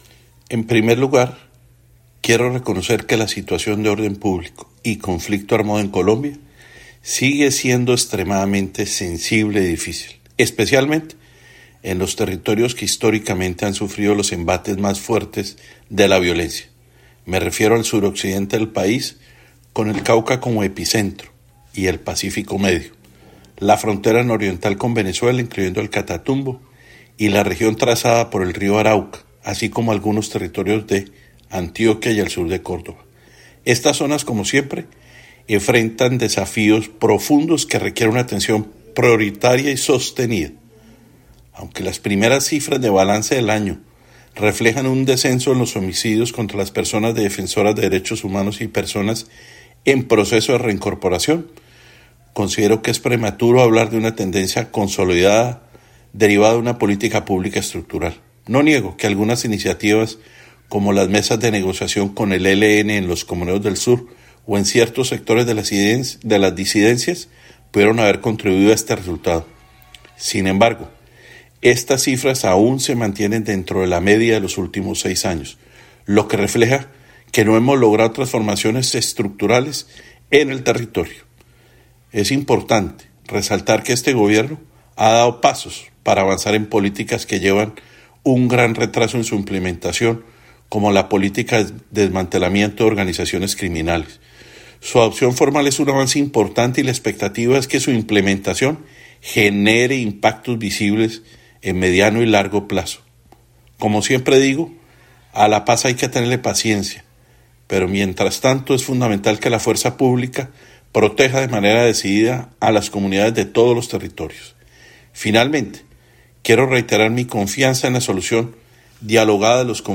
En declaraciones a Caracol Radio, el exdefensor del Pueblo Carlos Negret habló sobre la situación de orden público y el conflicto armado en Colombia durante 2024.